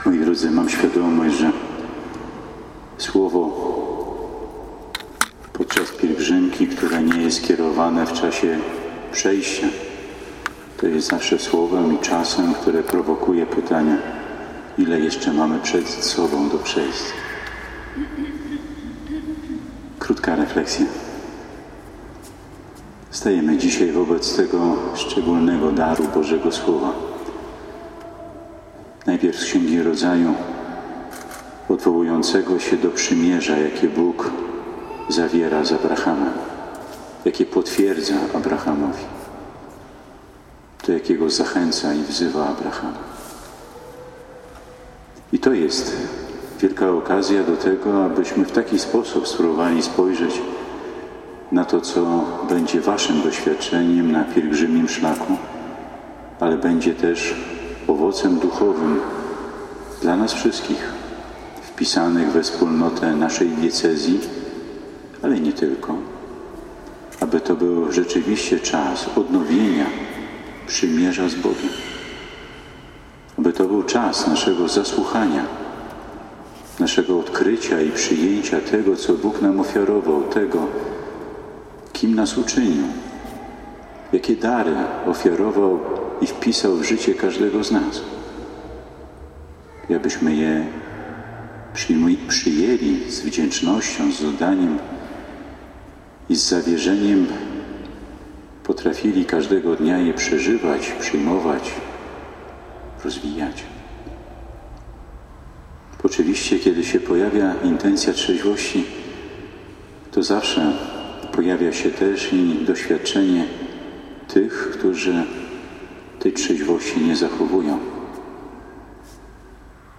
Mszy św. na jej rozpoczęcie w radomskiej katedrze przewodniczył bp Marek Solarczyk.
Ewangelia o uzdrowieniu trędowatego przez Jezusa stała się kanwą dla homilii, którą wygłosił bp Marek:
Bp Marek Solarczyk, homilia:
32_pielgrzymka_radom-niepokalanow_bpmsolarczyk_homilia.mp3